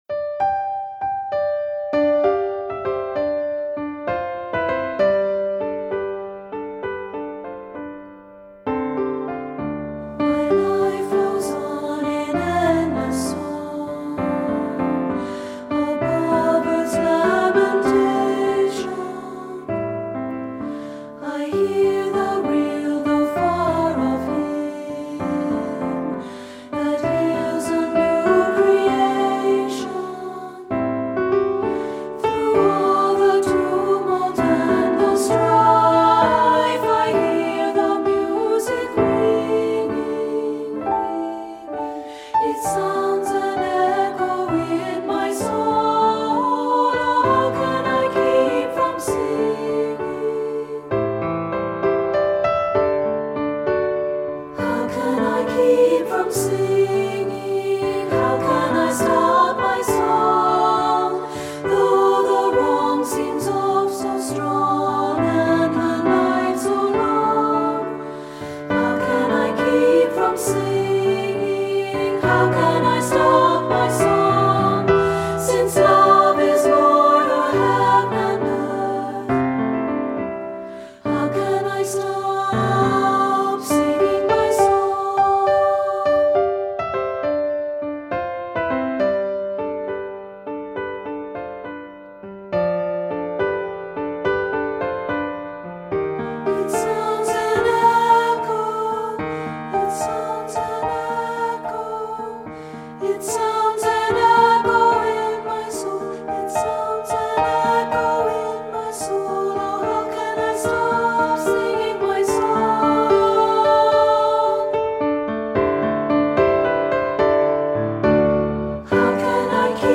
Voicing: 2-Part and Piano Level